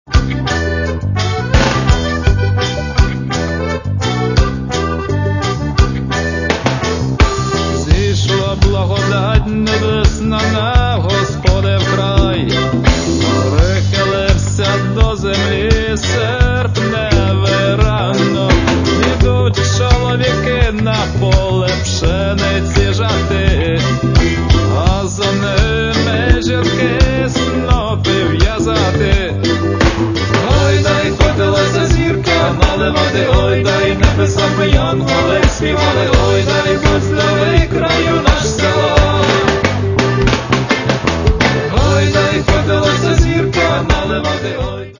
Каталог -> Рок и альтернатива -> Фольк рок
соединяя энергию украинских, цыганских, ямайских мелодий.